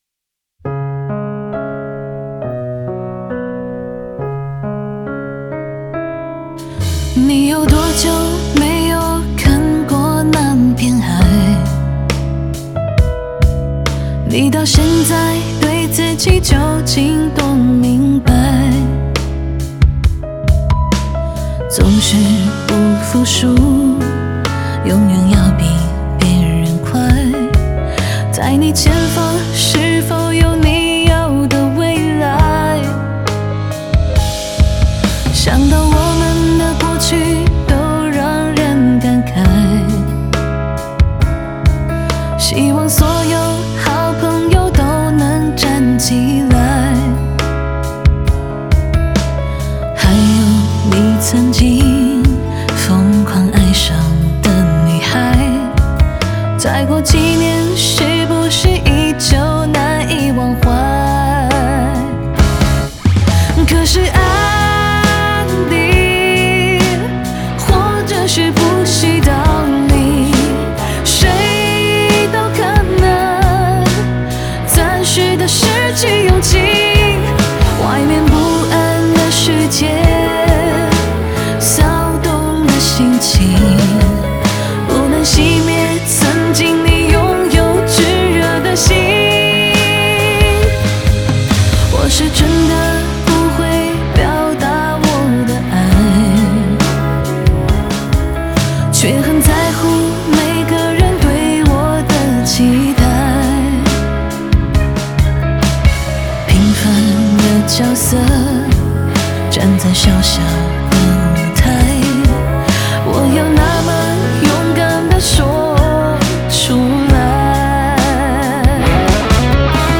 吉他Guitar
贝斯Bass
和声Backing Vocals